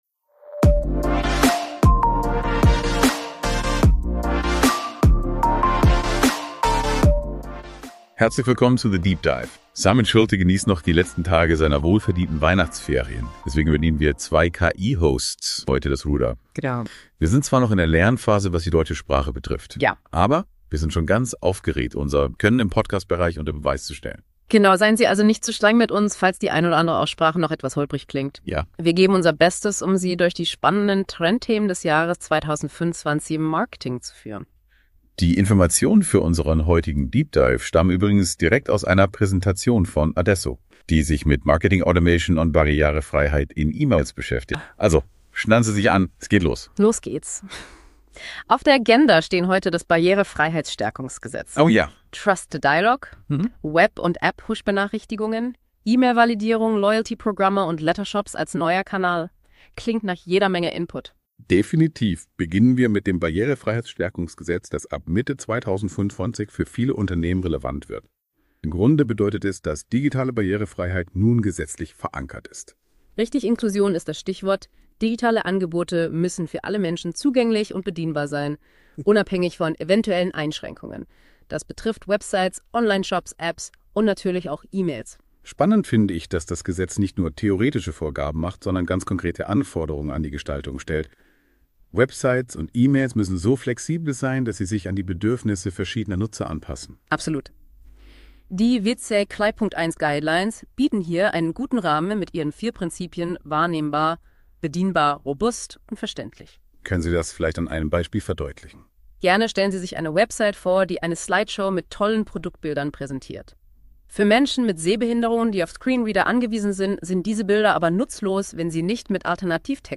Darum übernehmen in dieser Folge zwei KI-Hosts den CX/Magic Podcast. Was passiert, wenn Googles Notebook LM die Moderation übernimmt?